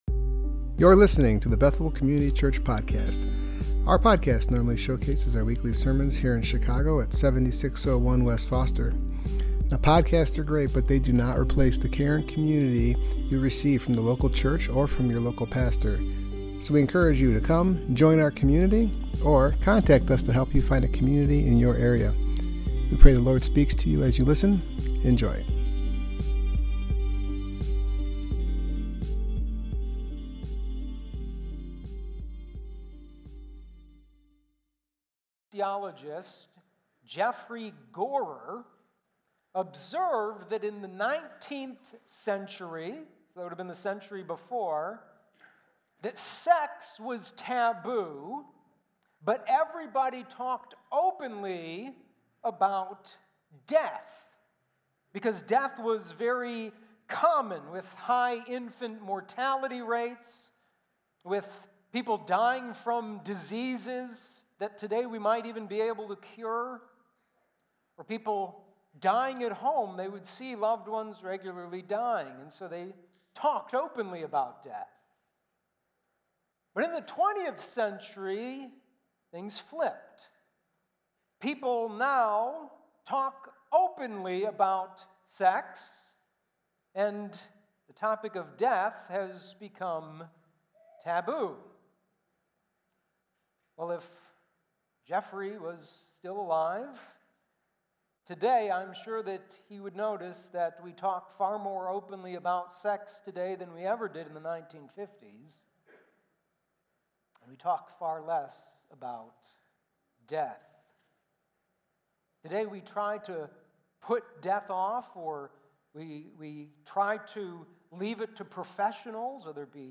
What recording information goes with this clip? Passage: Ecclesiastes 7:1-14 Service Type: Worship Gathering